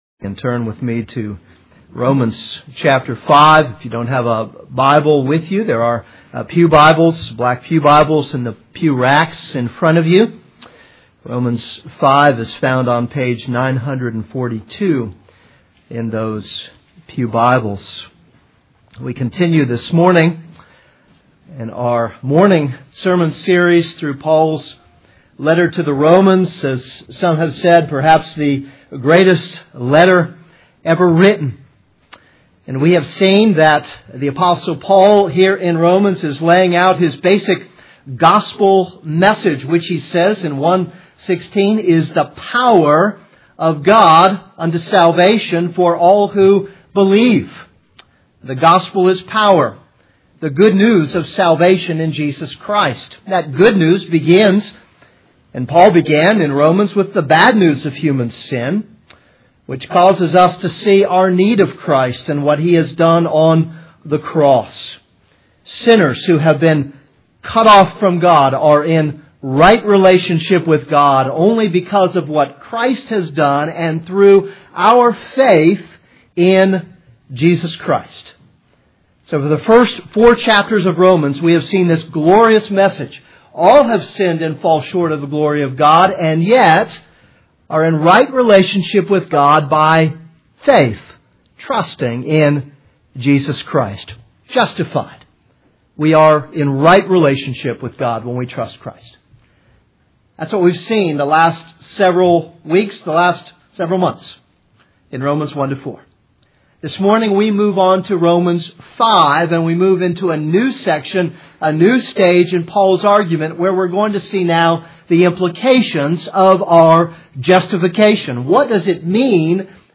This is a sermon on Romans 5:1-5.